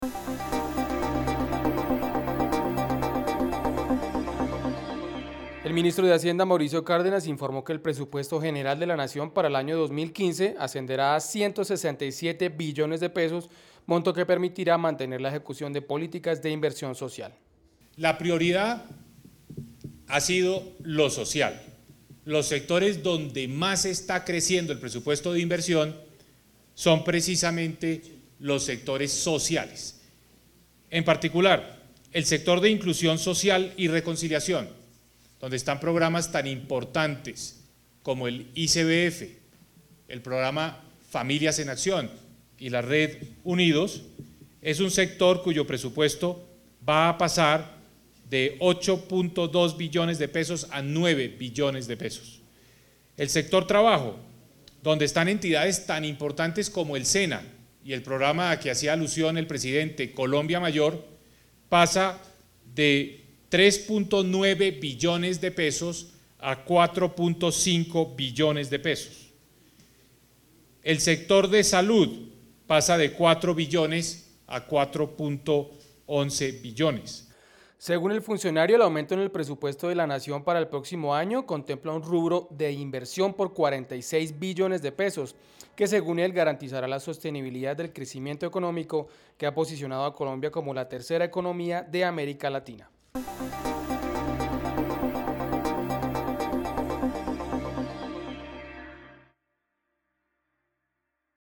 Suscríbete y escucha las noticias jurídicas narradas con IA.